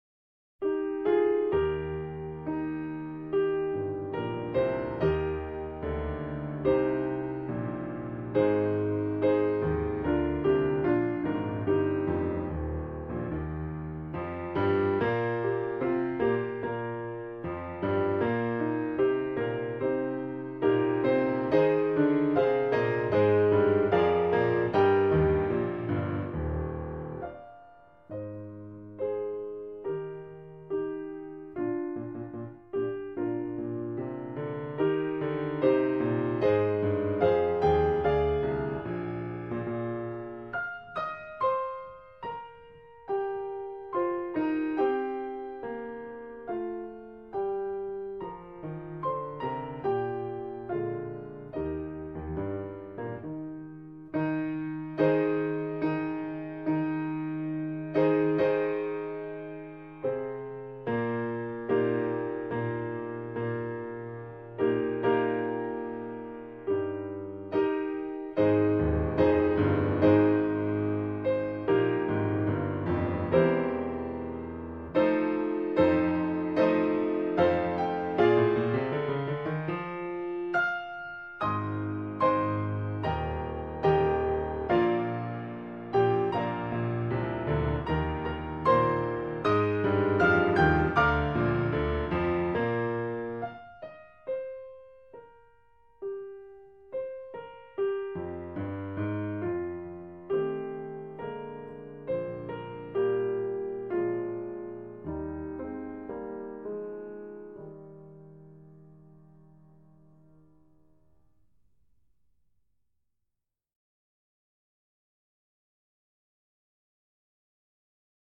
เพลงพระราชนิพนธ์